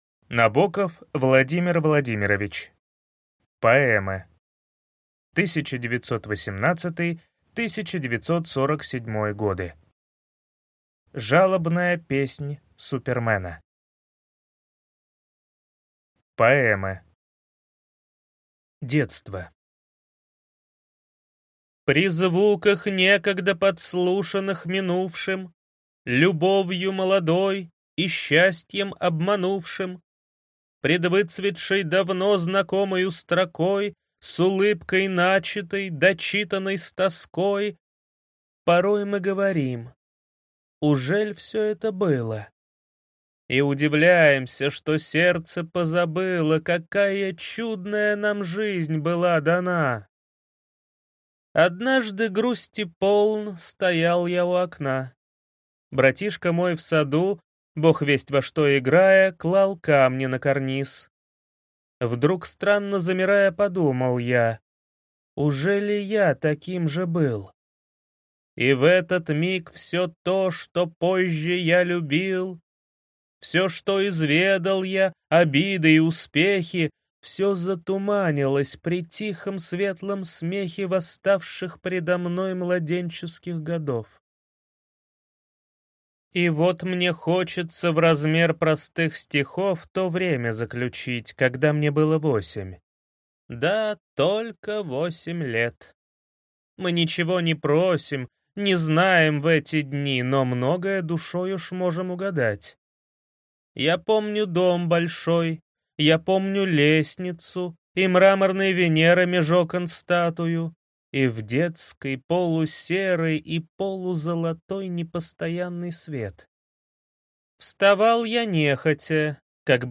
Аудиокнига Поэмы 1918-1947. Жалобная песнь Супермена | Библиотека аудиокниг